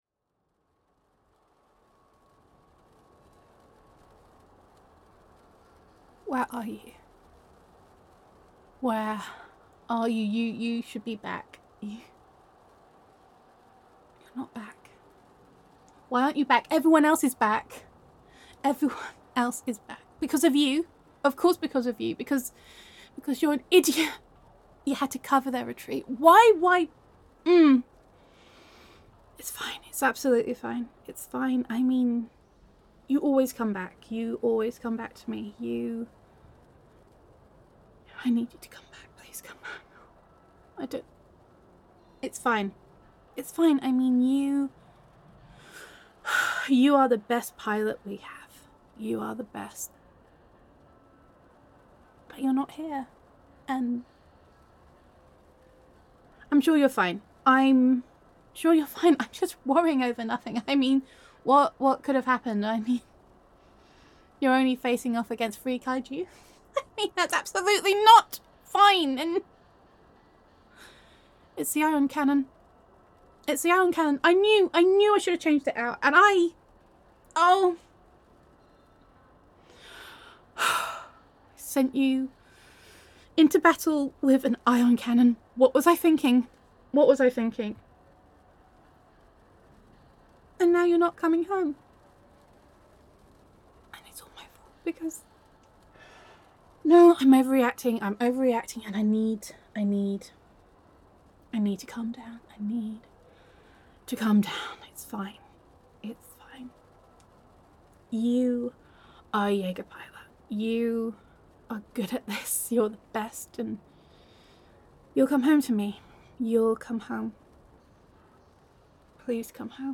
[F4A]
[Sci Fi Roleplay]